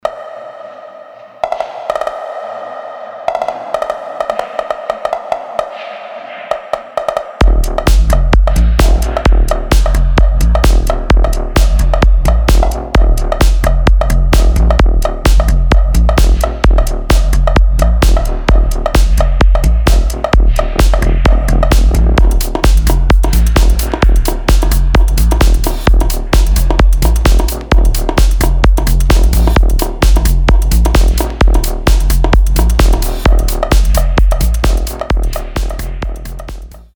минимал
tech house , bass house , техно , без слов